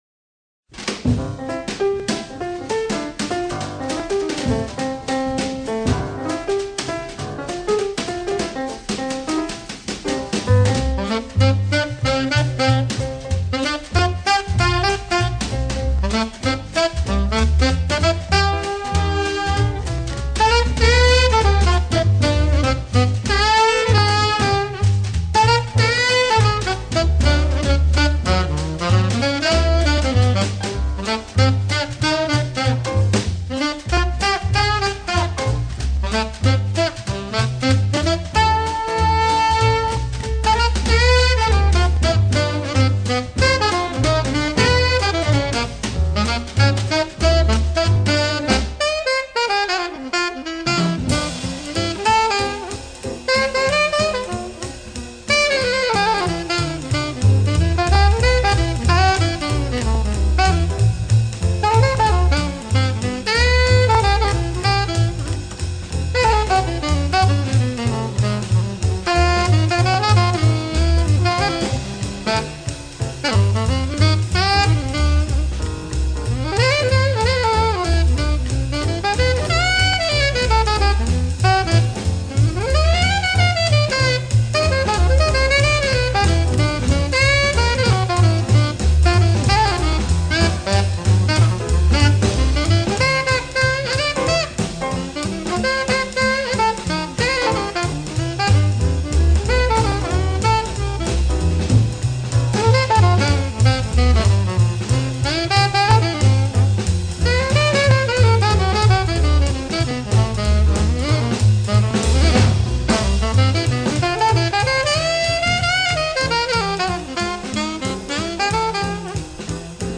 saxo eta flautajolearen
baxua maisukiro jotzen duen
tronpetajolearen